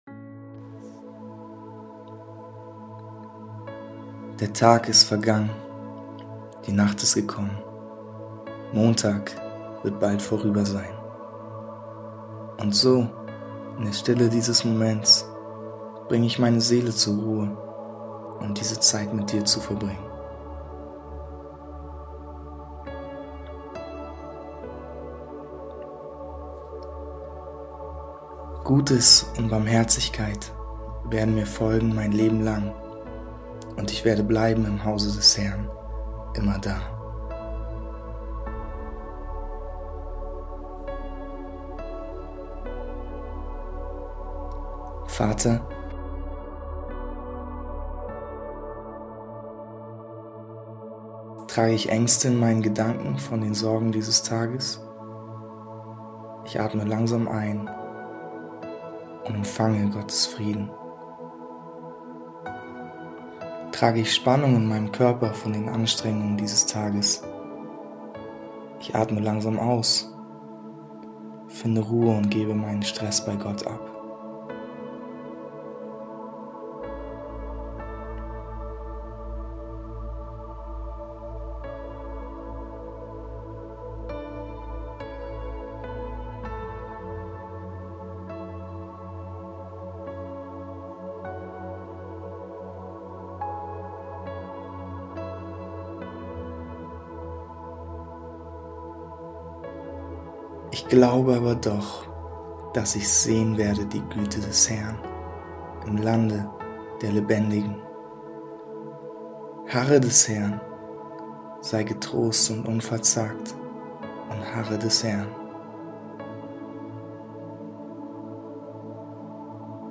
Abendmeditation am 11.09.23